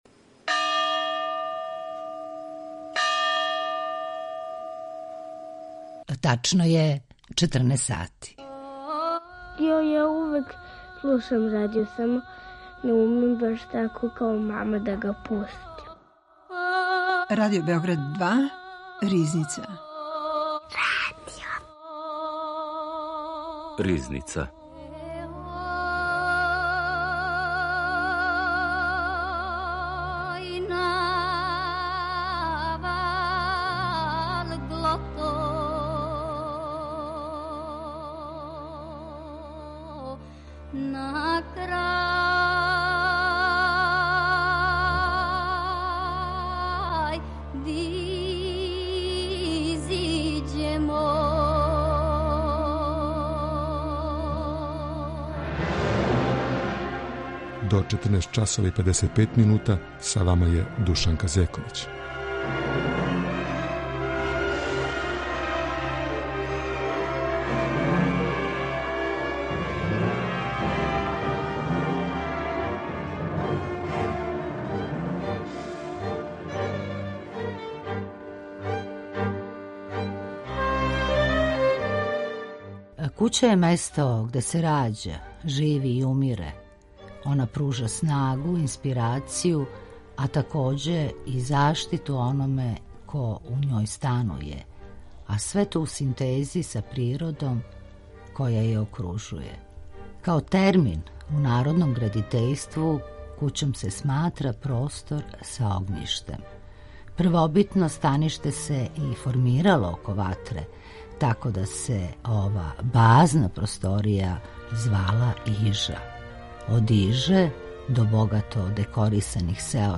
Аудио подкаст